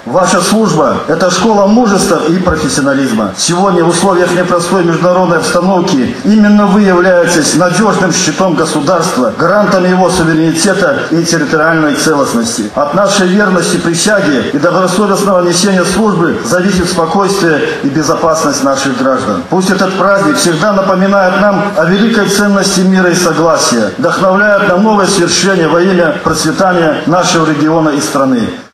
На страже мирной жизни. Торжественное мероприятие состоялась в городском Доме культуры Барановичей
Ваша служба – это школа мужества и профессионализма, — подчеркнул, обращаясь к собравшимся, замглавы района Михаил Борисевич.